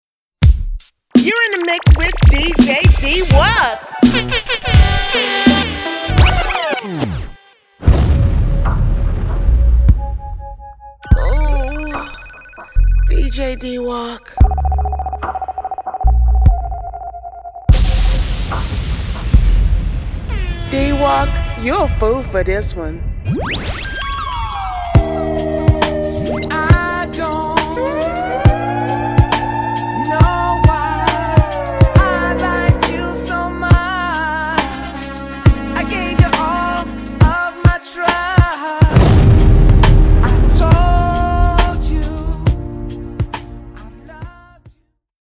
Urban Female DJ Drops
Stand out with our personalized urban female DJ drops, professionally recorded by an African American voice artist. Authentic accent.
Urban-female-DJ-drops-demo.mp3